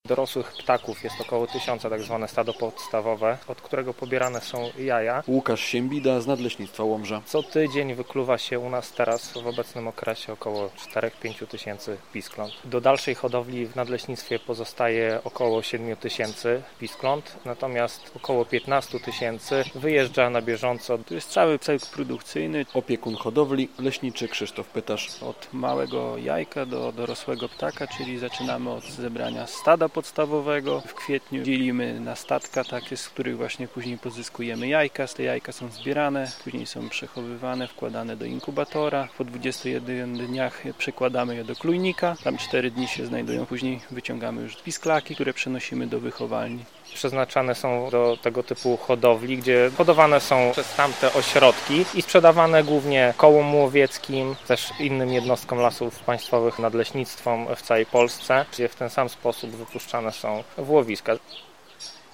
Hodowla bażantów w Elżbiecinie - relacja